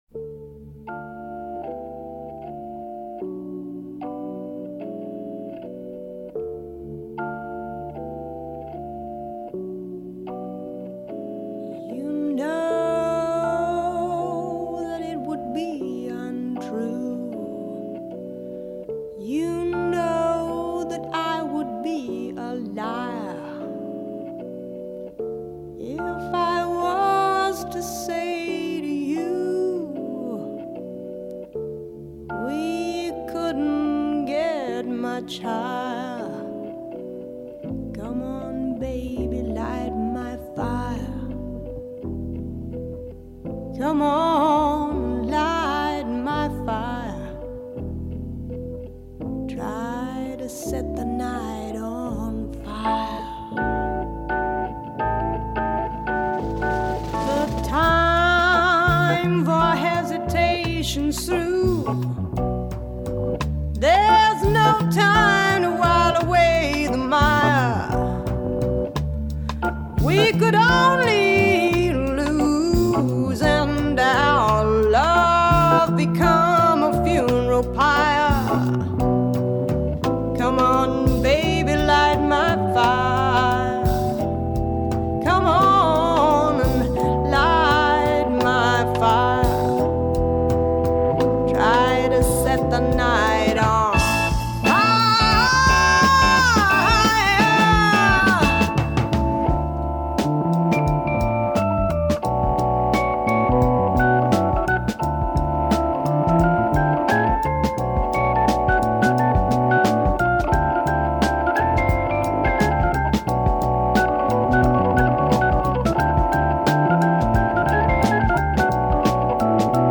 soul singer
Psych soul and jazz